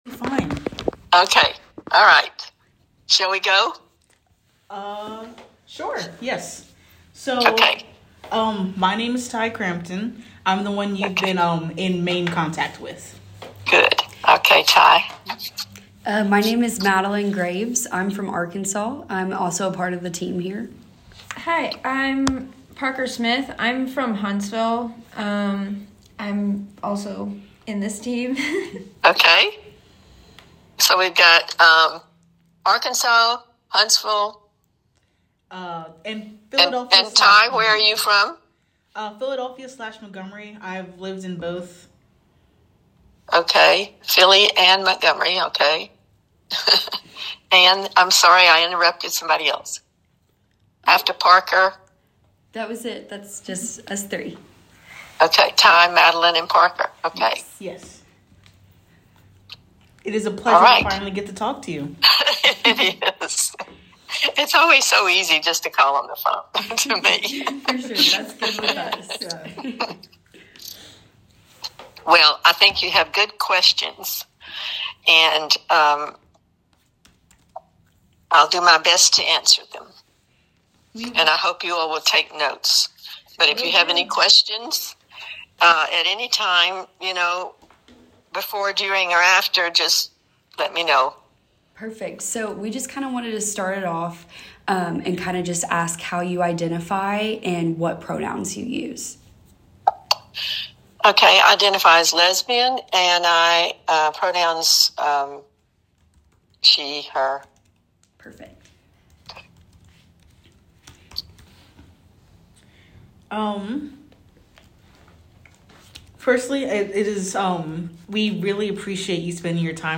oral history interview
over the phone